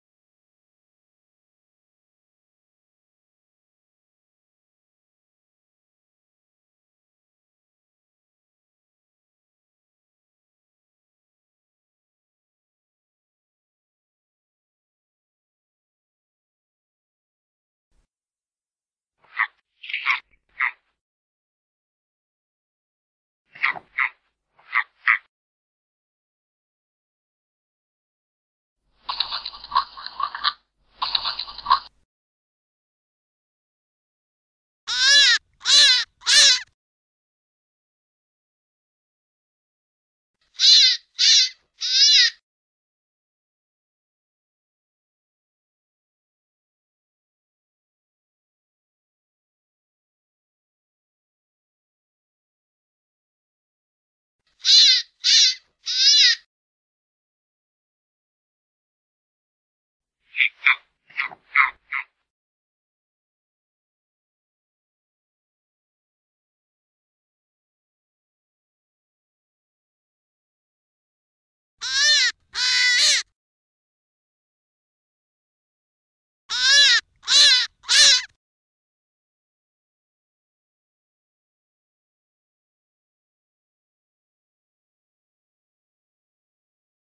SONIDOS DE LIEBRES Y CONEJOS     HARE SOUNDS AND RABBITS.mp3